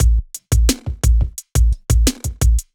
Drum Loops (19).wav